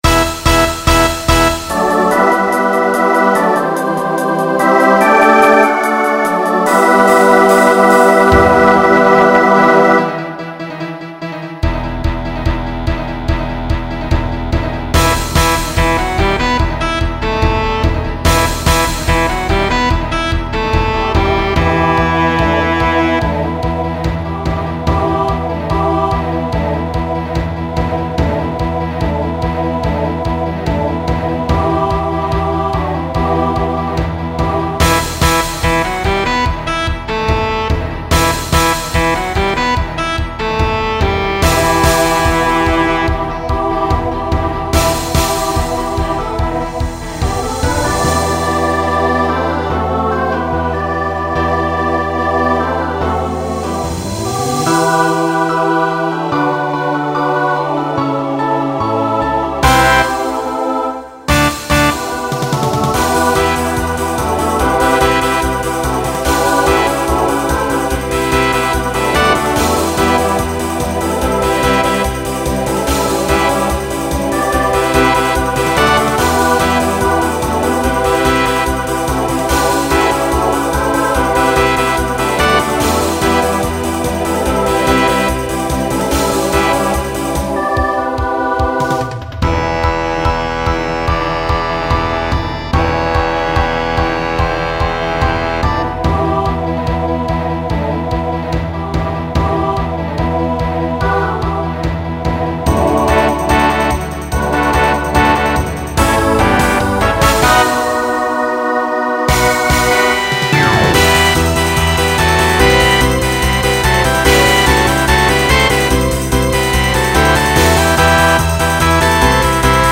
Genre Rock
Opener Voicing SATB